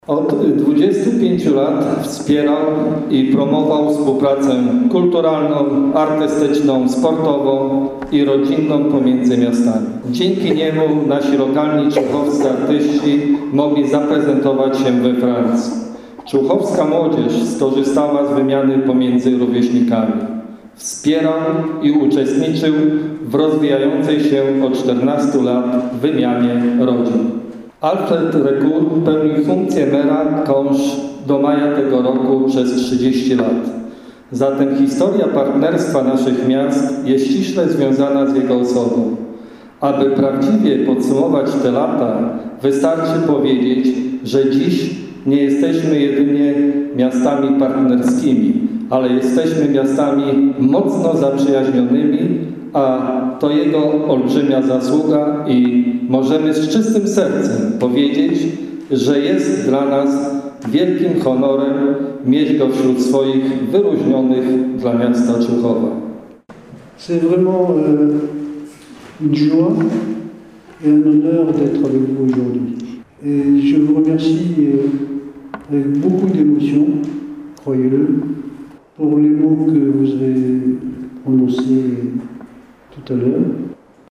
Uroczystość jego wręczenia odbyła się w człuchowskim ratuszu.
Po ceremonii wręczenia tytułu głos zabrał sam wyróżniony.